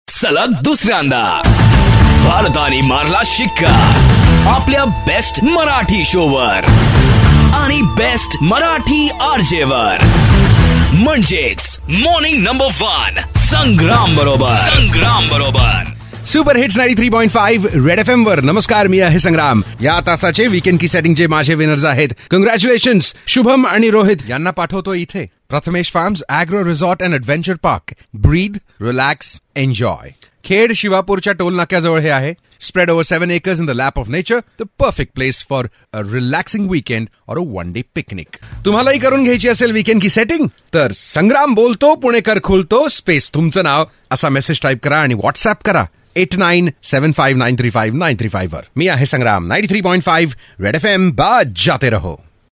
India Ka no. 1 Marathi Radio Show.